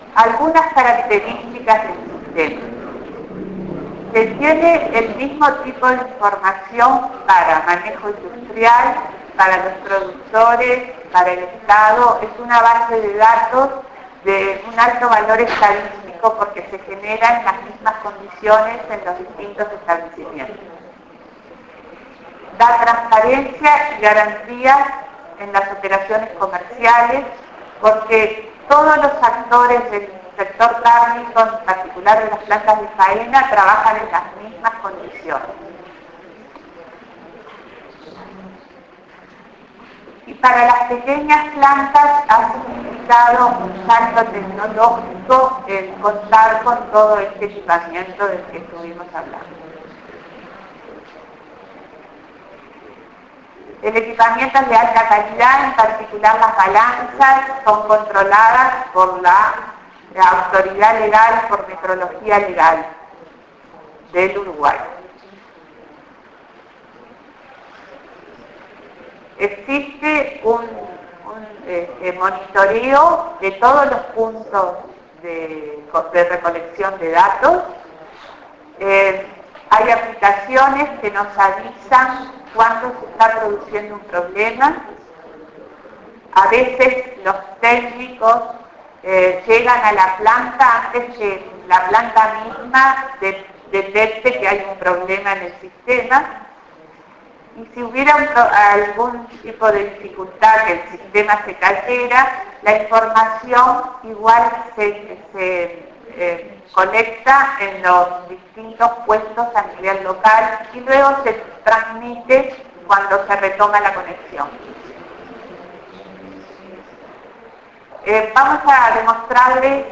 Exposición ante misión coreana